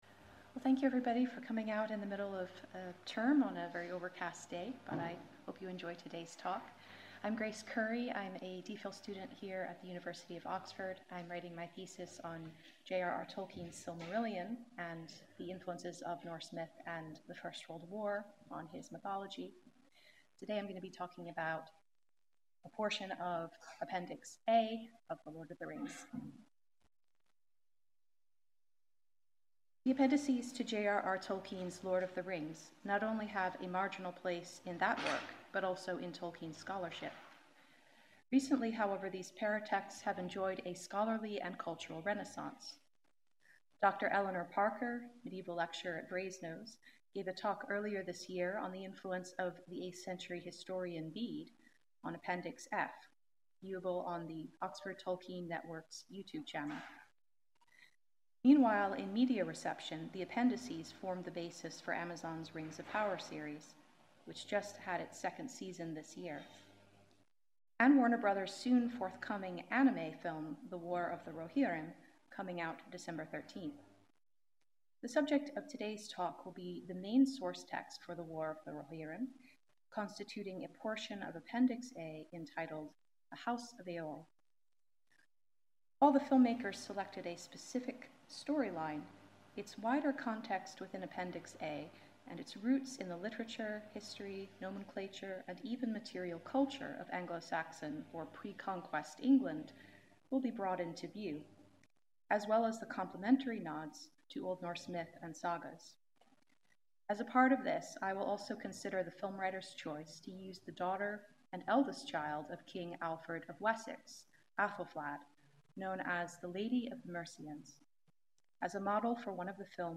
Part of the 70th anniversary talks on 'The Lord of the Rings' organised by the Faculty of English and Exeter College.